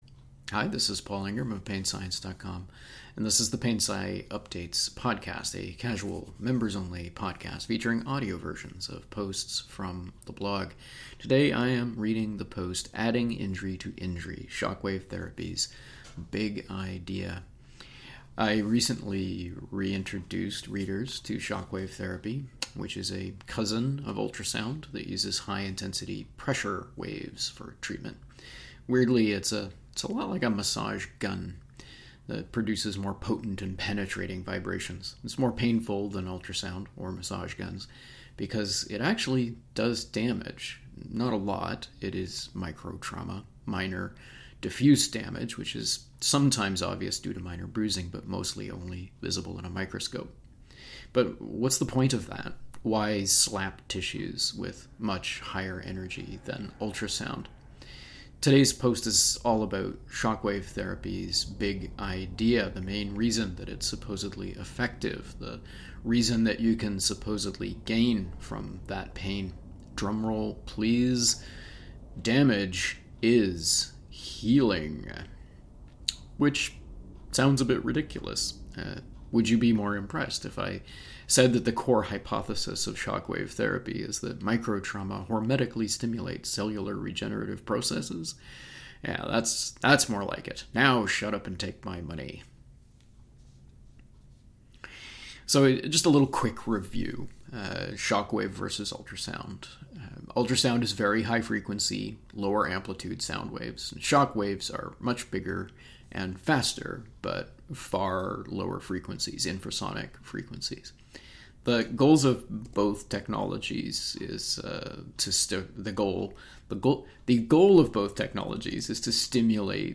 Play the members-only audio version of this post. Audio versions usually include some digressions.